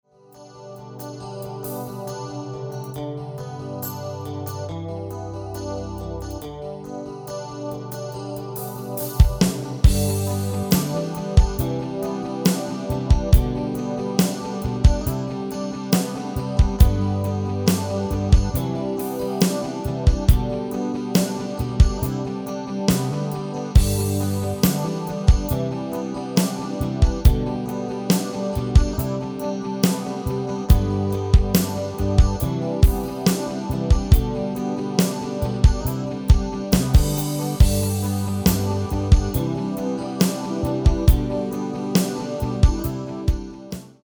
Demo/Koop midifile
- GM = General Midi level 1
- Géén vocal harmony tracks
Demo's zijn eigen opnames van onze digitale arrangementen.